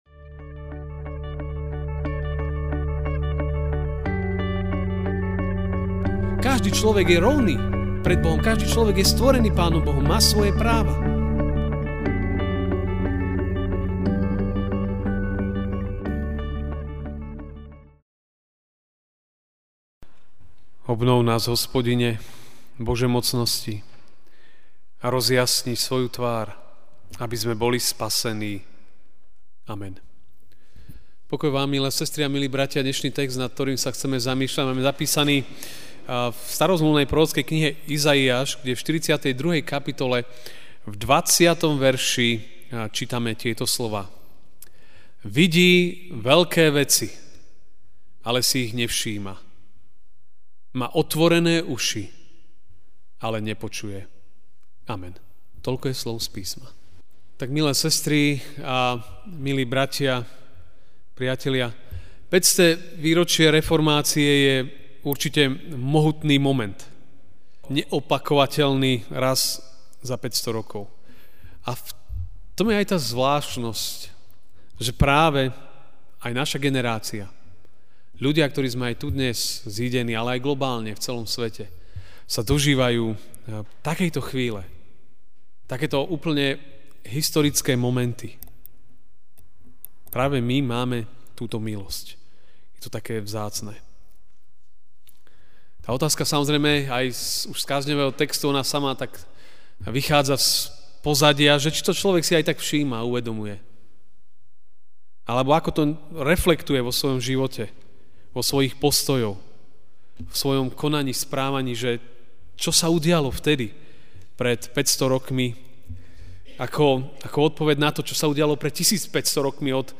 okt 31, 2017 Reformácia MP3 SUBSCRIBE on iTunes(Podcast) Notes Sermons in this Series Ranná kázeň: Reformácia (Izaiáš 42:20) Vidí veľké veci, ale si ich nevšíma, má otvorené uši, ale nepočuje.